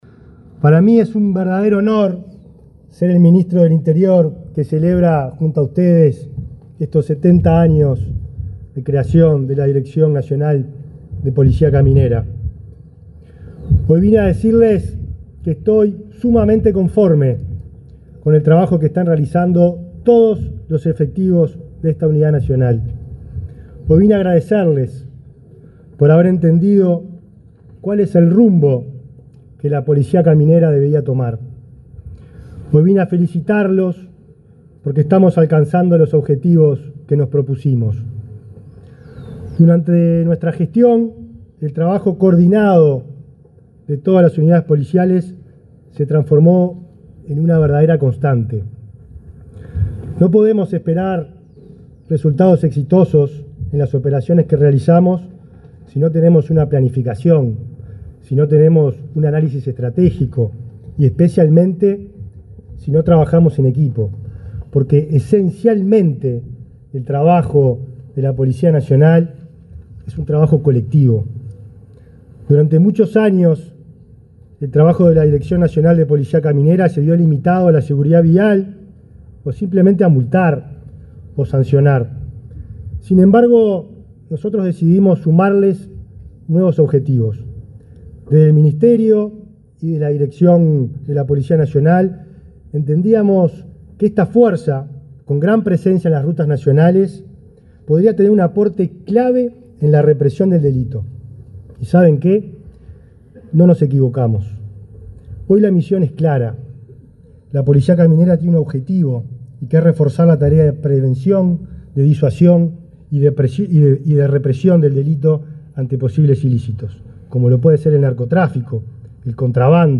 Palabras del ministro del Interior, Nicolás Martinelli
Palabras del ministro del Interior, Nicolás Martinelli 16/09/2024 Compartir Facebook X Copiar enlace WhatsApp LinkedIn El ministro del Interior, Nicolás Martinelli, participó, este lunes 16 en Montevideo, en el acto conmemorativo del 70.° aniversario de la Dirección Nacional de Policía Caminera.